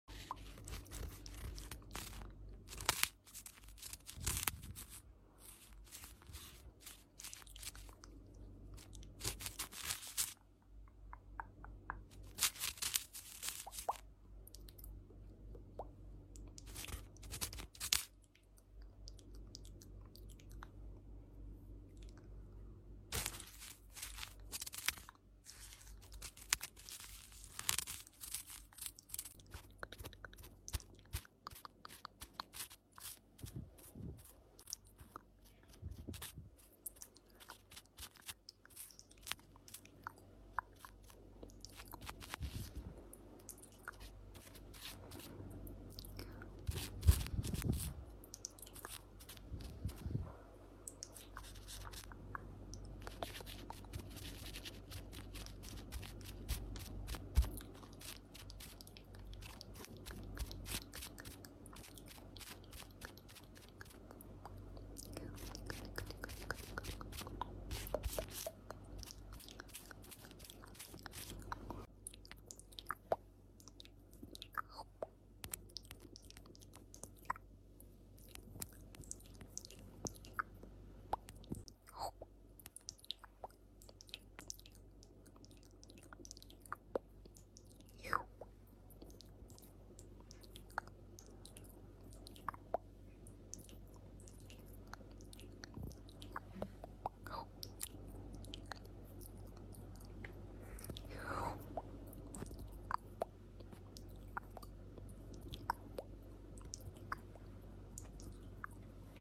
Asmr close-up nighttime bedtime care